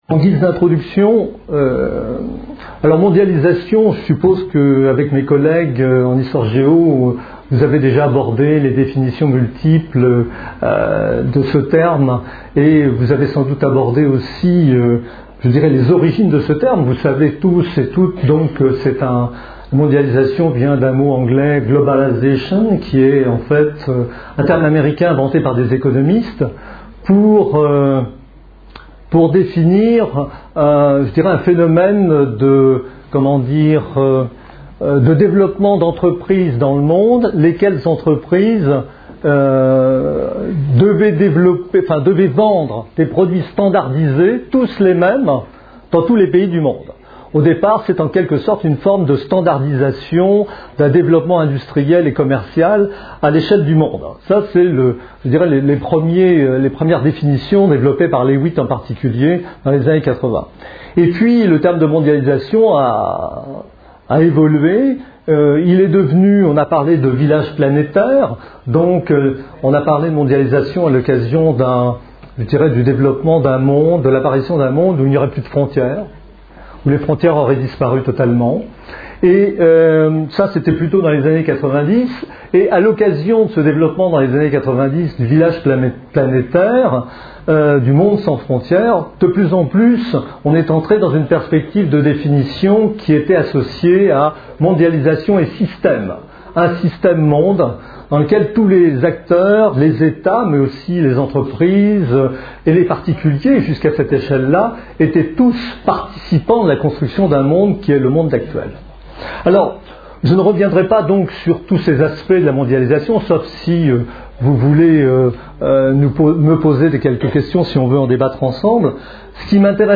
Une conférence de l'UTLS au LycéeLa mondialisation et l'Europe